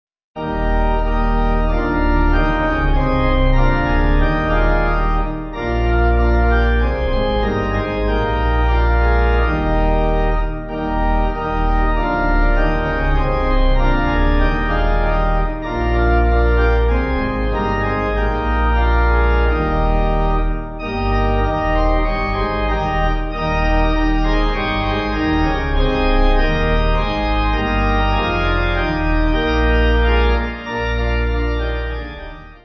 (CM)   4/Eb